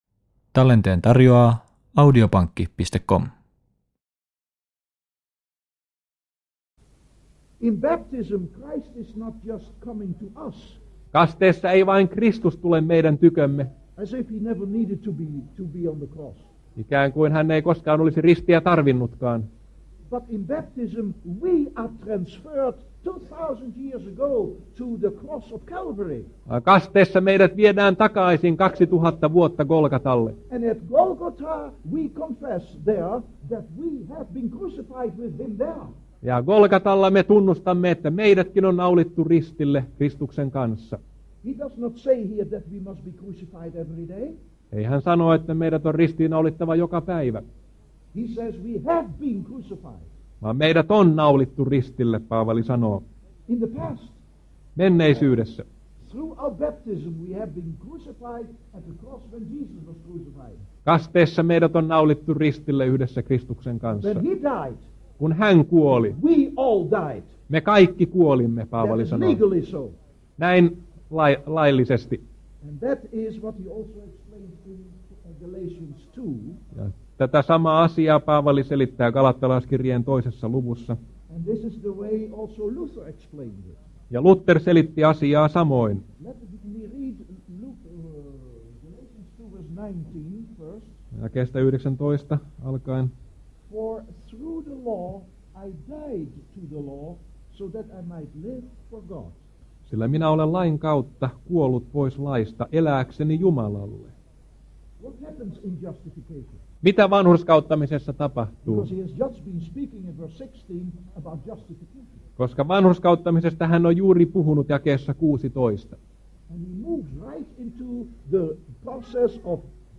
Kallioniemessä elokuussa 1988